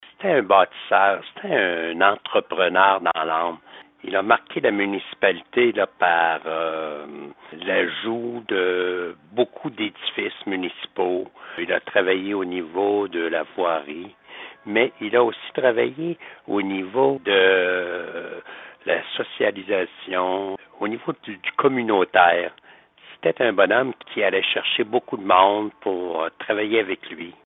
Celui qui occupe le poste depuis ce temps, Maurice Grimard, affirme que ce personnage a été marquant pour le développement de sa municipalité.